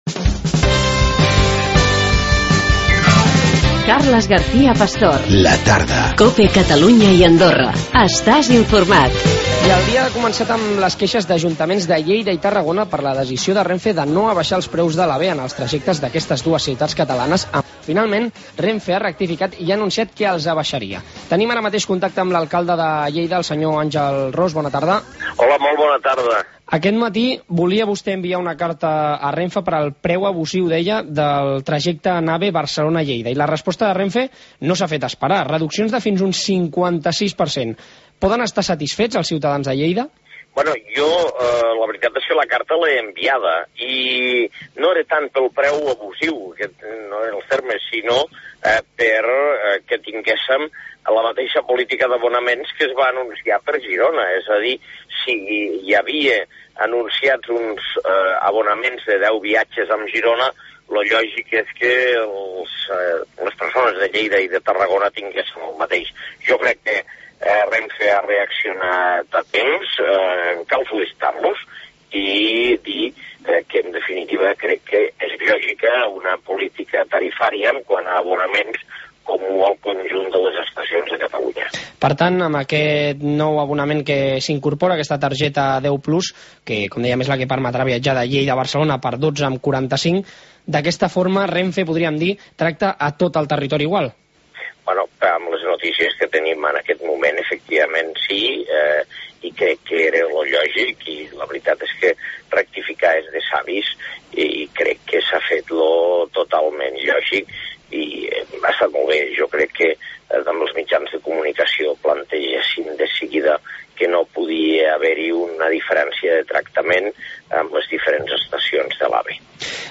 AUDIO: Àngel Ros , Alcalde de Lleida, ens parla de la rebaixa del trajecte Barcelona – Lleida amb l’AVE.